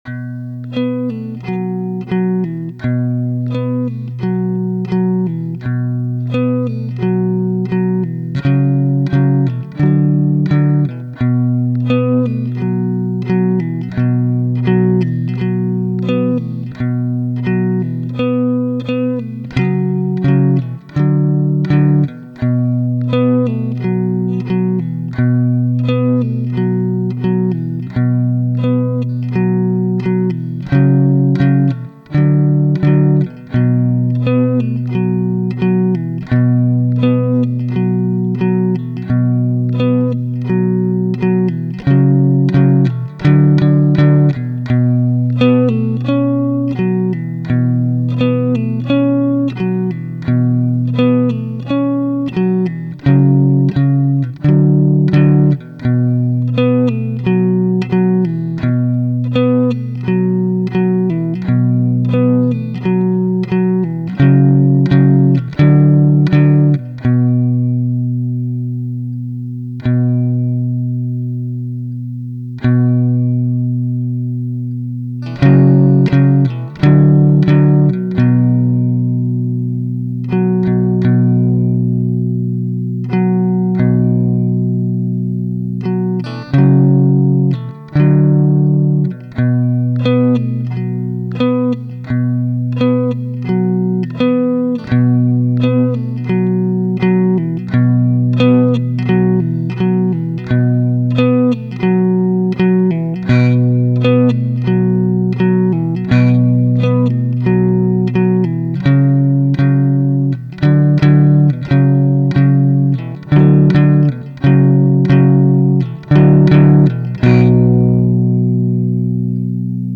[Bac à sable] Impro guitare 01
Je vous propose une petite impro guitare pour s'amuser ensemble.
BPM : 86 Base : un accord de Si (B) mineur Gamme : Si (B) mineure naturelle